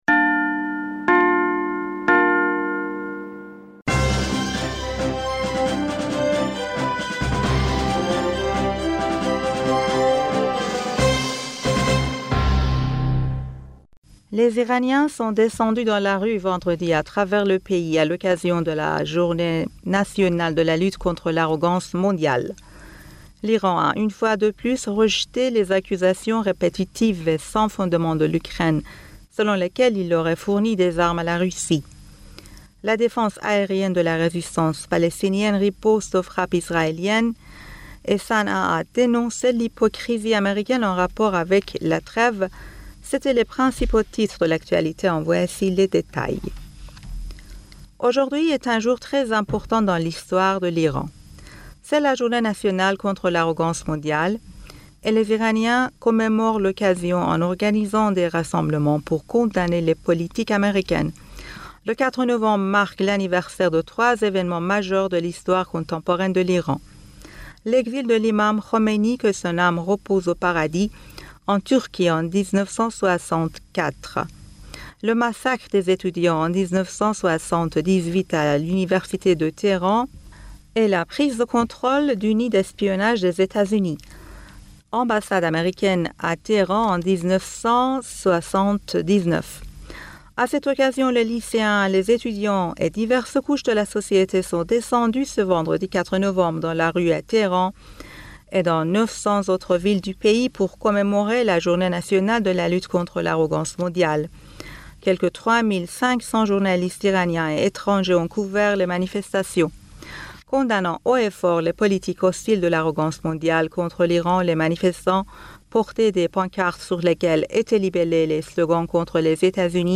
Bulletin d'information Du 04 Novembre